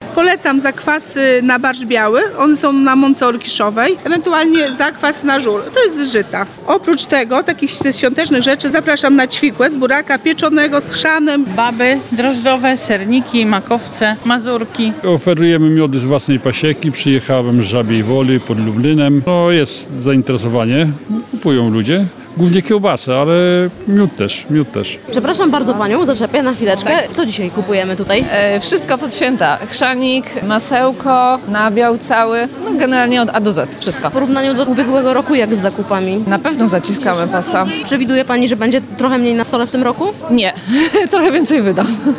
Wędlina wędzona w dymie olchowym, miody, świeże sery, mleko, pieczywo na naturalnym zakwasie czy ciasta – wszystko z ekologicznych gospodarstw. W Lublinie trwa Jarmark Wielkanocny przy ul. Lubartowskiej 77.